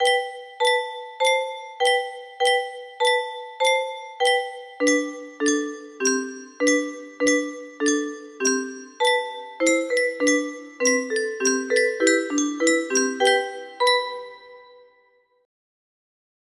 Track 1 � music box melody